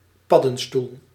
Ääntäminen
US : IPA : [ˈmʌʃ.rʊm]